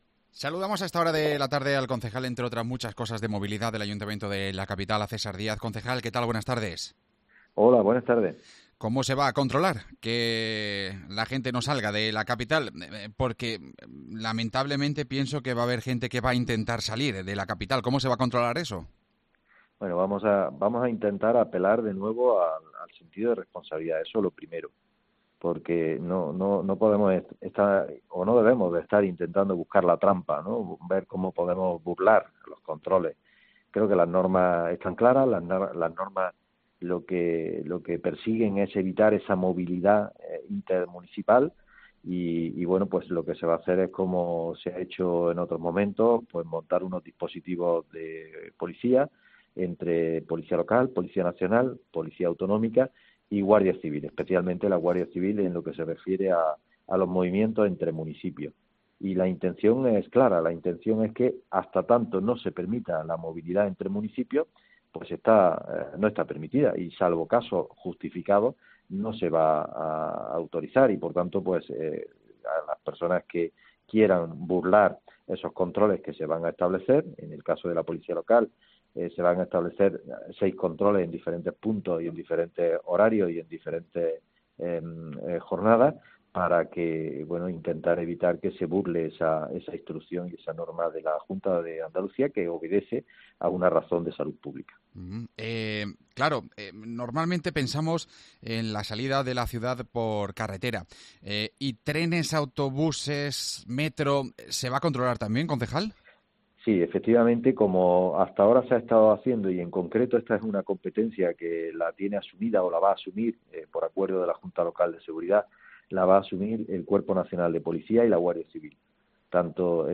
El concejal de protección ciudadana, César Díaz, detalla el dispositivo para controlar la movilidad